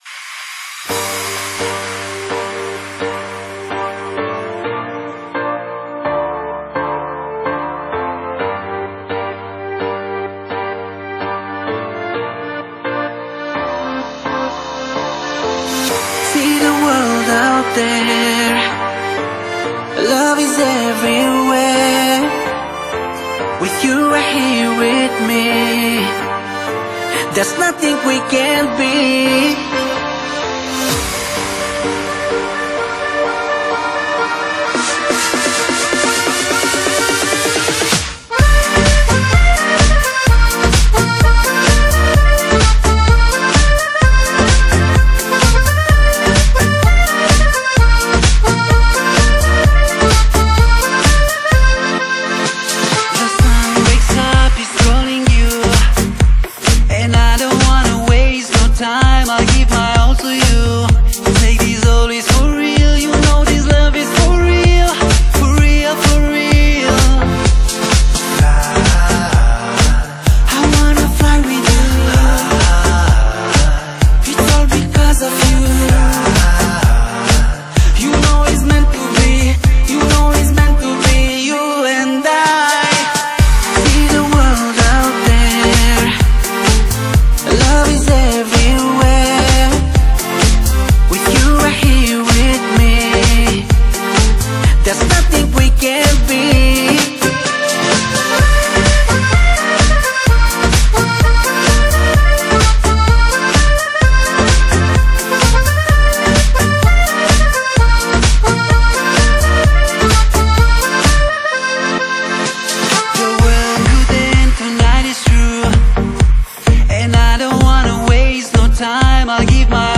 Genre: Dance, Pop | 22Tracks |  (百度盤)=219 M
呵呵音乐节奏性很强谢谢楼主分享